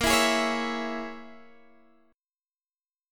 A#m7b5 chord